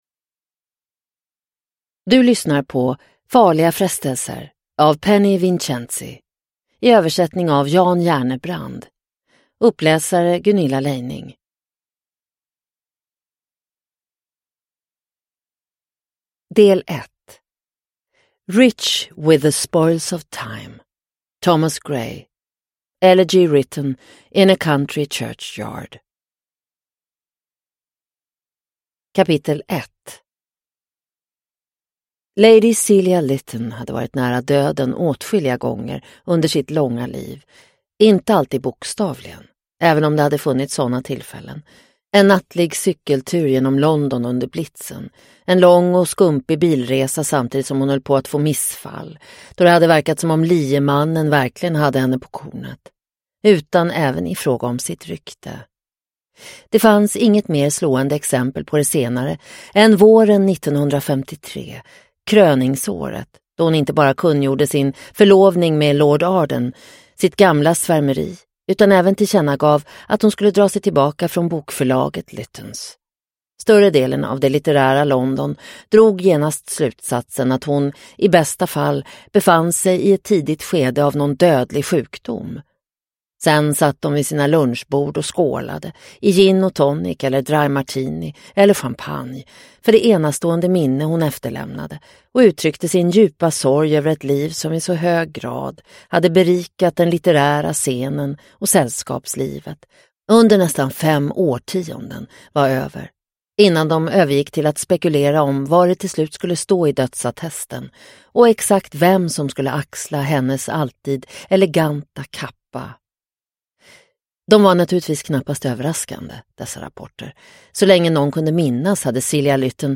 Farliga frestelser – Ljudbok – Laddas ner